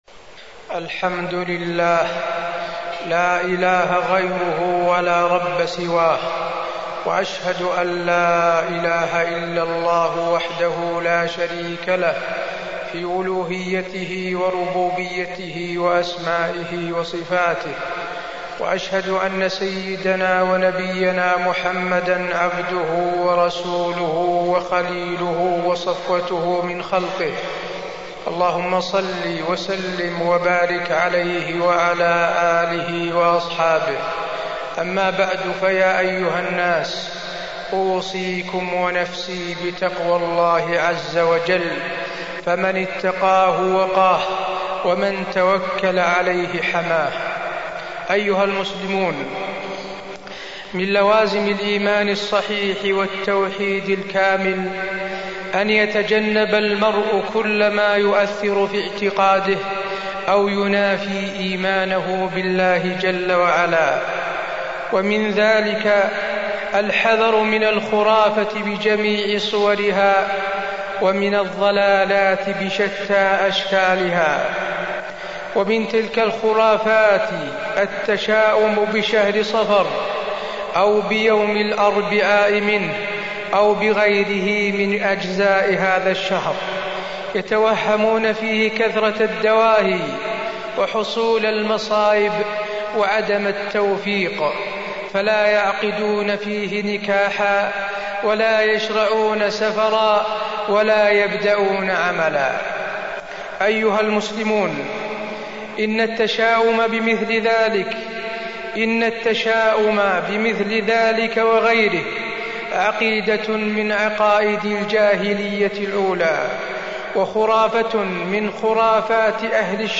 تاريخ النشر ٨ صفر ١٤٢٦ هـ المكان: المسجد النبوي الشيخ: فضيلة الشيخ د. حسين بن عبدالعزيز آل الشيخ فضيلة الشيخ د. حسين بن عبدالعزيز آل الشيخ التطير والتشاؤم